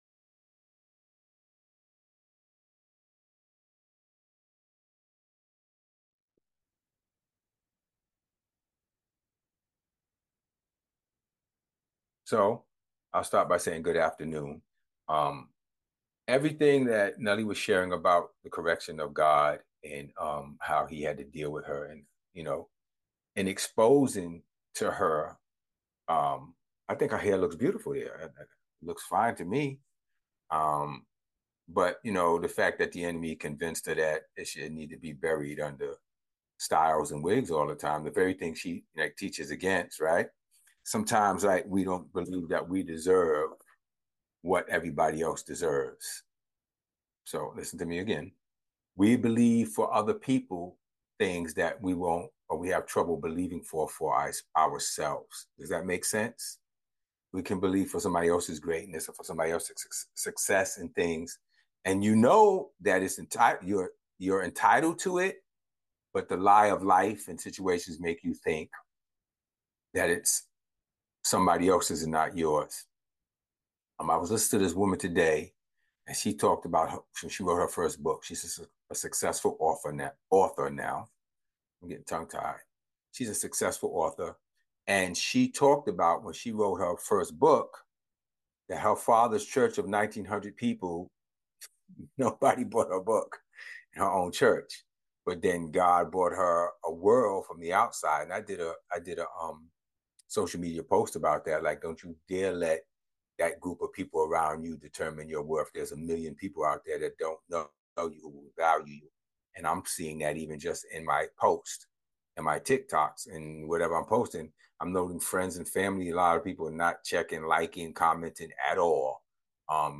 In this week’s message we learned that God loves us, but his love for us is not conditional.